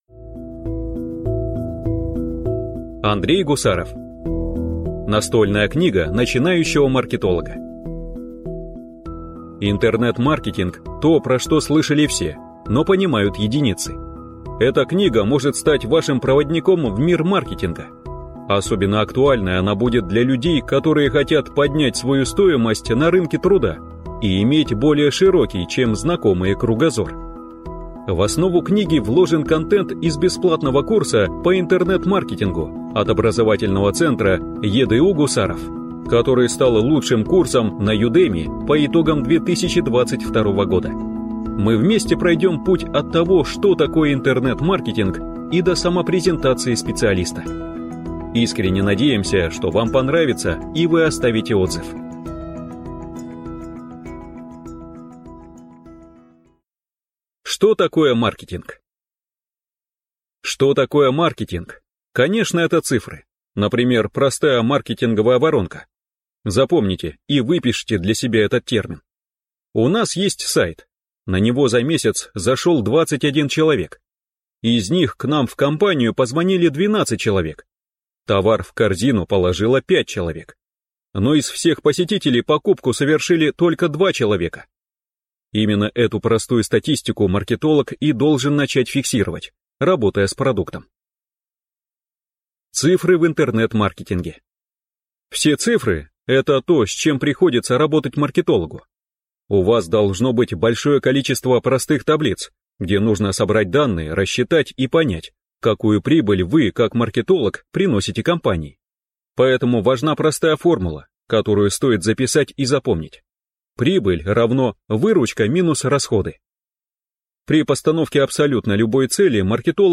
Аудиокнига Настольная книга начинающего маркетолога | Библиотека аудиокниг